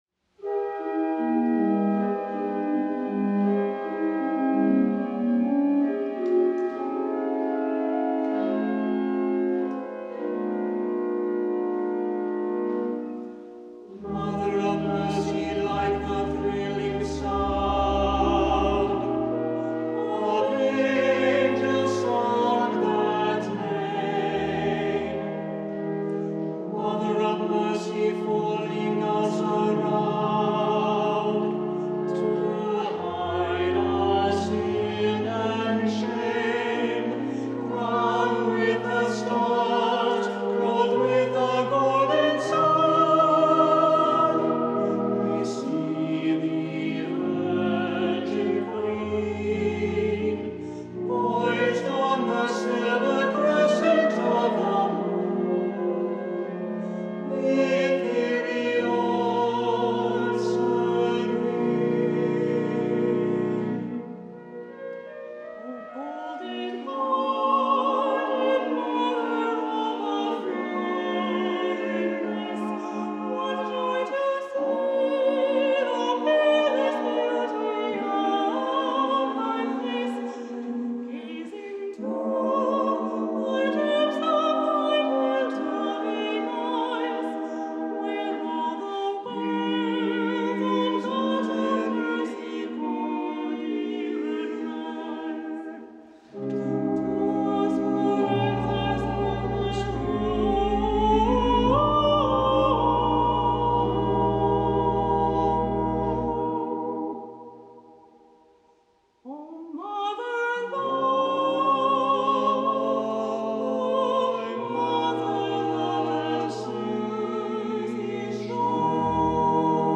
The organ has 62 stops and close to 3,200 pipes.
On October 3, 2022, we recorded the following hymns at the Basilica of Our Lady of Perpetual Help:
organ